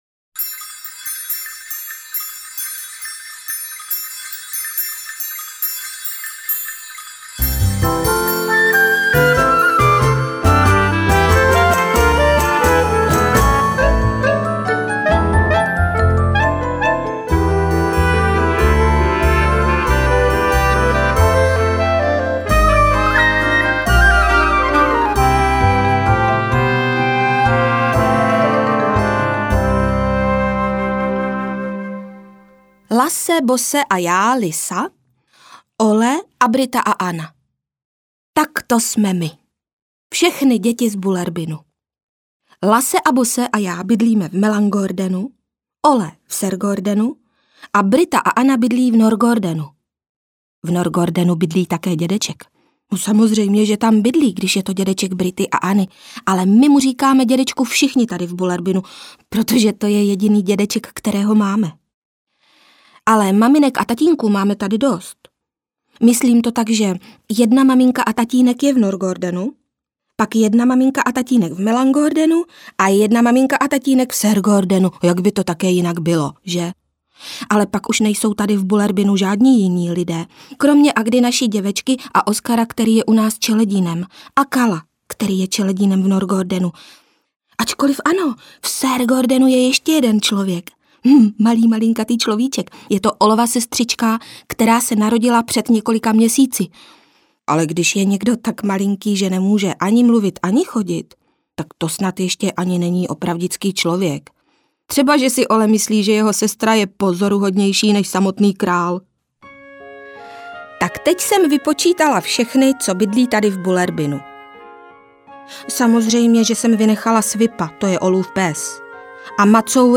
Vánoce v Bullerbynu audiokniha
Ukázka z knihy
Pro její úspěch jsme připravili další pokračování, opět s hlasem Libuše Šafránkové, tentokrát však vypráví příběhy ze zimního Bullerbynu.
• InterpretLibuše Šafránková